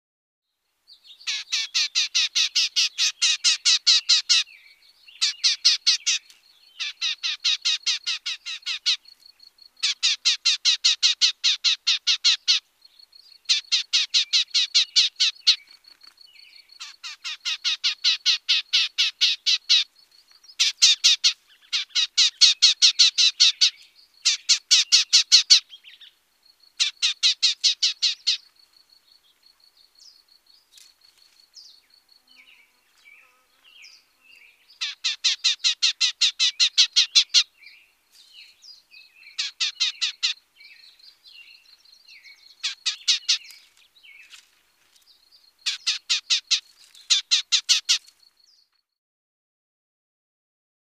Gnatcatcher | Sneak On The Lot
Gnatcatcher, Black-tailed Chirps. High-pitched, Mocking Chirps In The Foreground With Other Birds And Insects In The Background. Some Fluttering And Flapping Movements Are Also Heard.